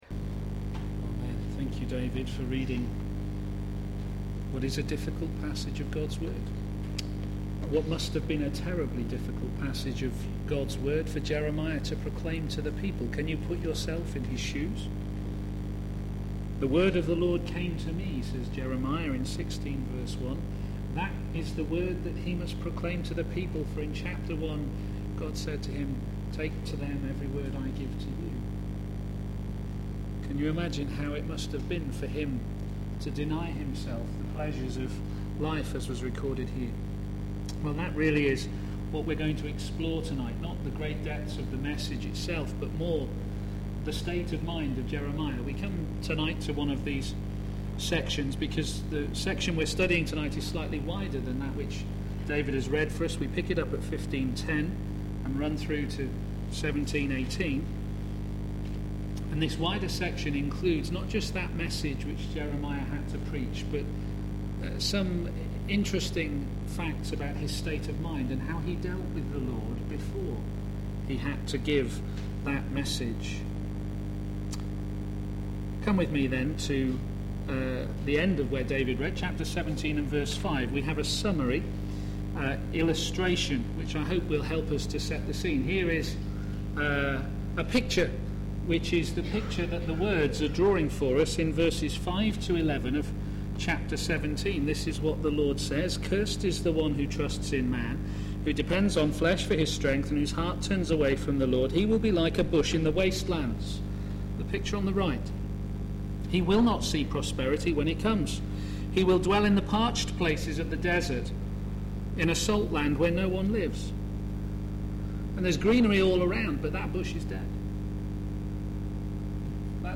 p.m. Service
Sermon